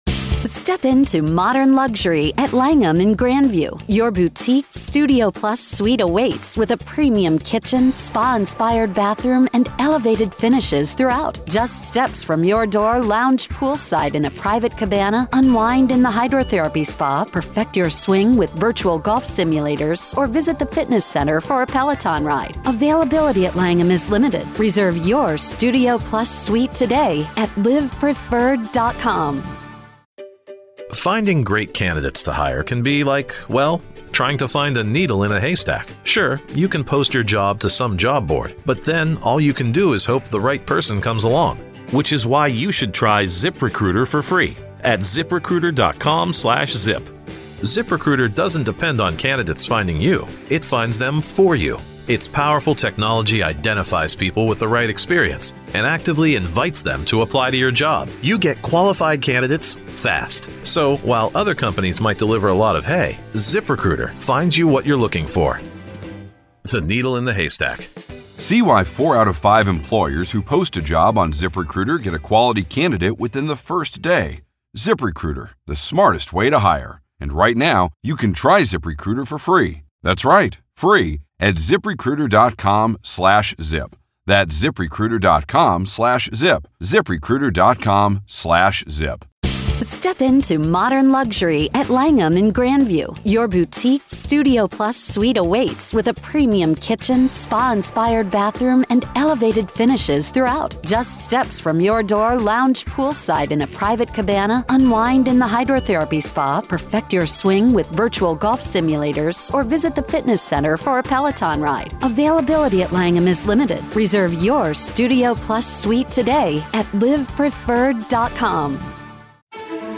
In today's motions hearing for the Karen Read case, the court addressed several key issues ahead of her retrial scheduled for April.